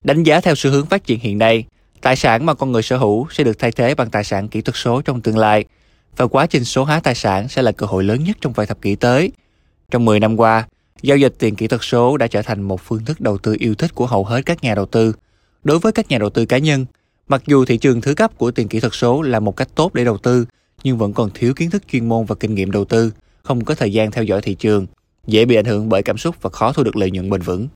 旁白解说-娓娓道来